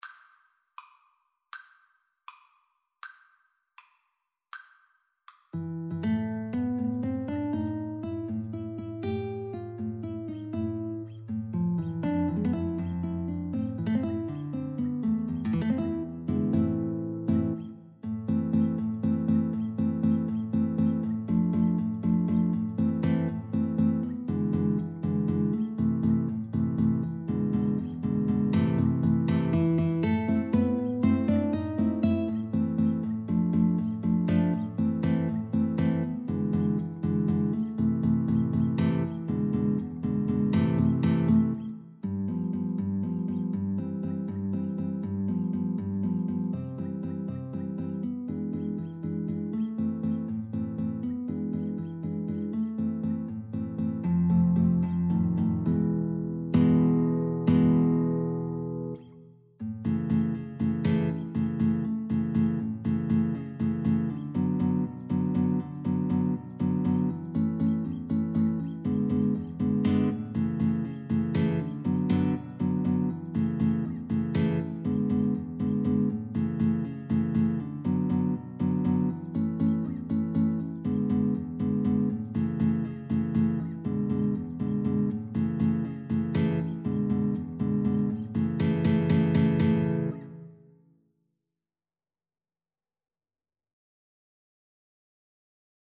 Free Sheet music for Violin-Guitar Duet
A minor (Sounding Pitch) (View more A minor Music for Violin-Guitar Duet )
Molto energico . = c. 80
6/8 (View more 6/8 Music)
Classical (View more Classical Violin-Guitar Duet Music)
world (View more world Violin-Guitar Duet Music)